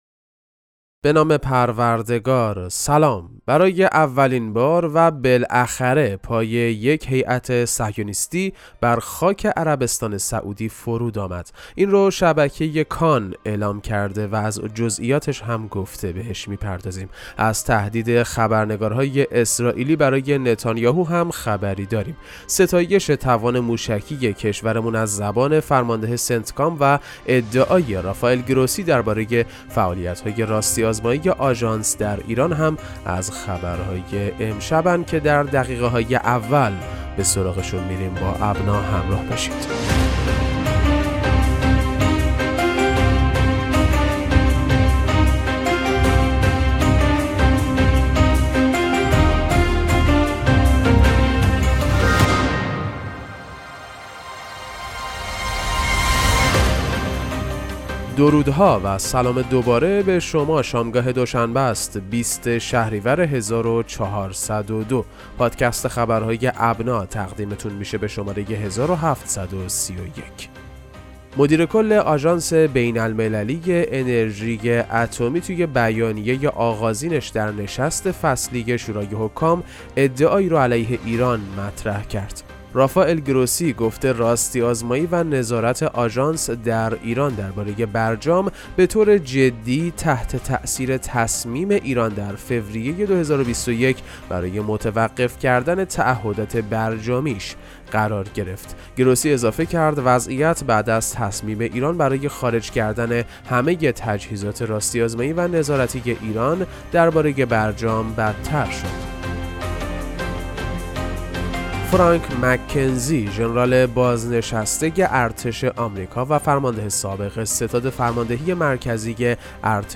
پادکست مهم‌ترین اخبار ابنا فارسی ــ 20 شهریور 1402